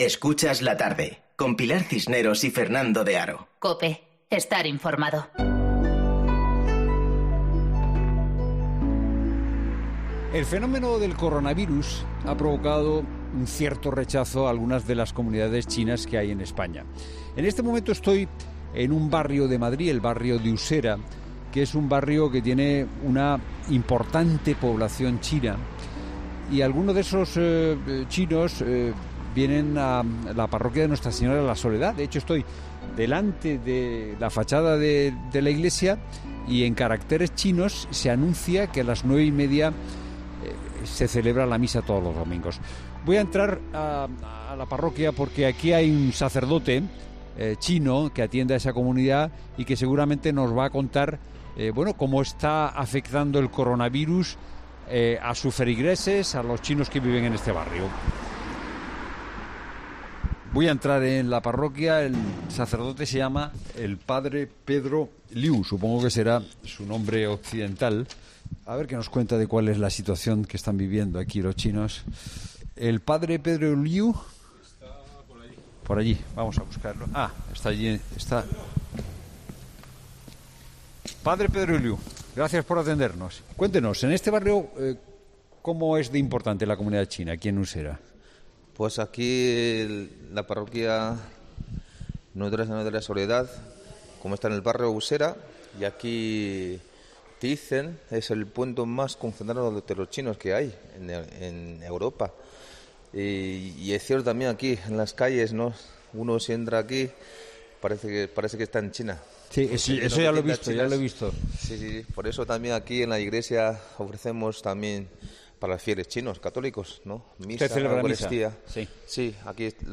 Escucha el reportaje entero y viaja a la china profunda de Madrid para descubrir esta realidad, a visitar sus calles, a conocer la reacción de sus gentes